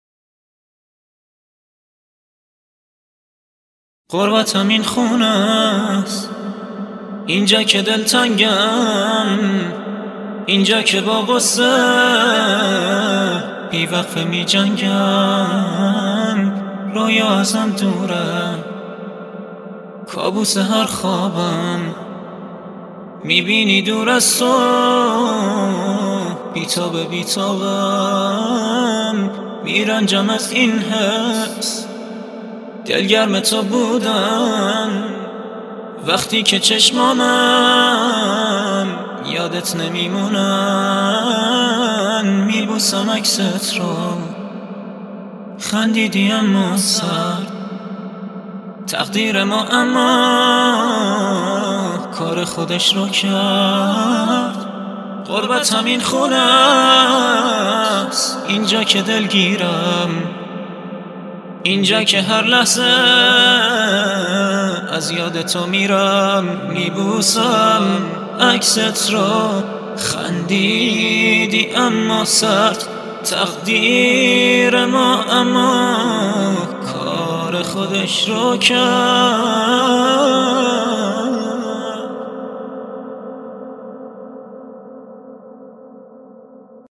an acapella vocal